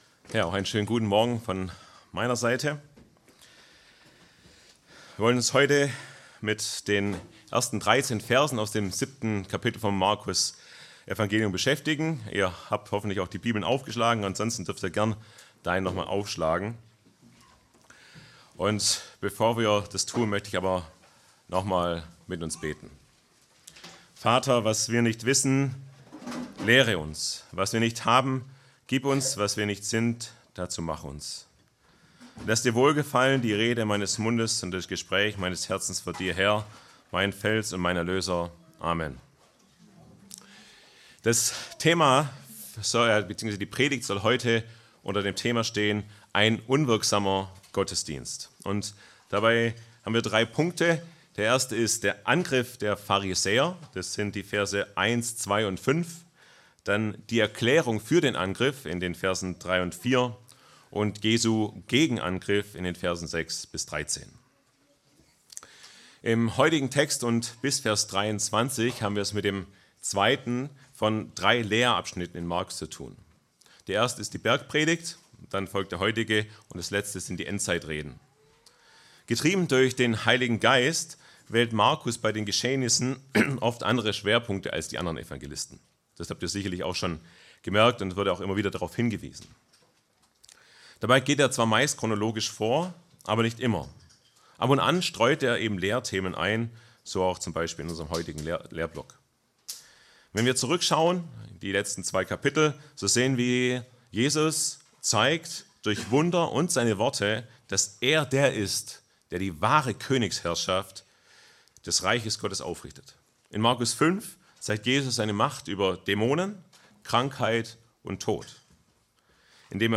Predigtreihe: Markusevangelium